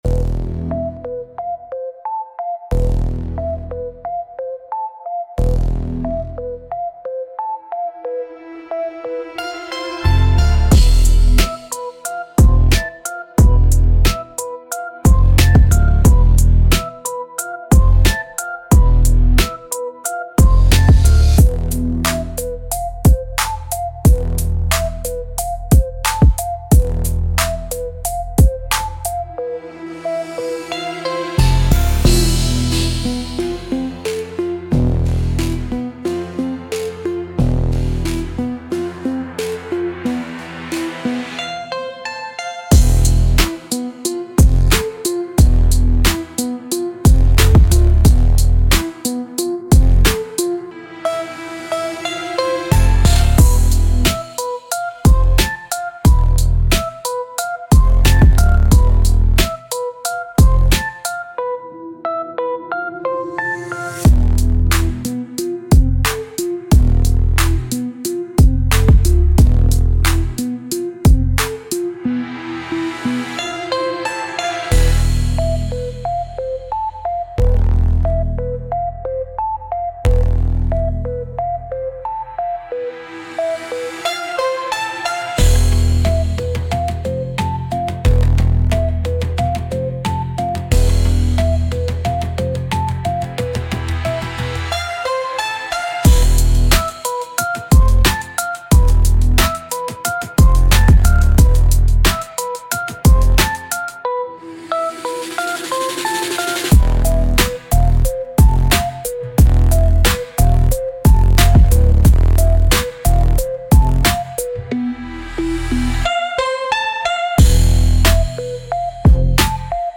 Instrumental - Echoes in the Chest - 4.00 Mins